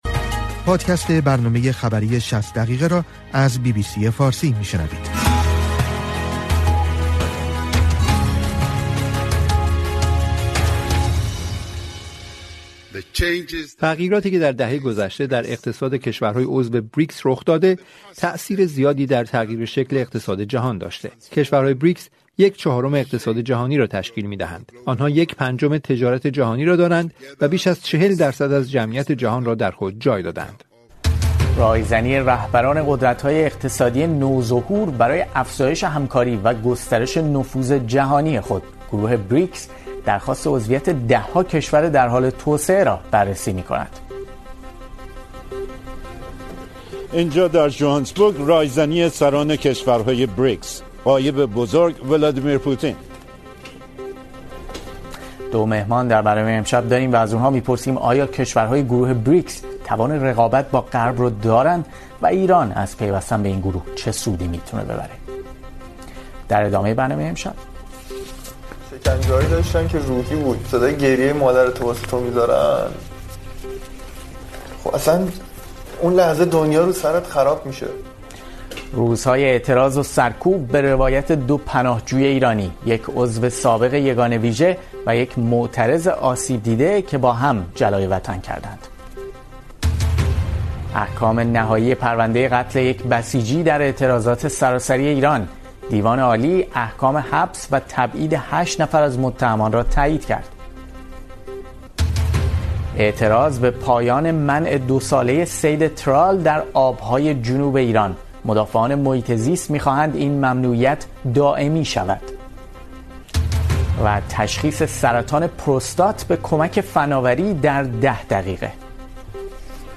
برنامه خبری ۶۰ دقیقه سه‌شنبه ۳۱ مرداد ۱۴۰۲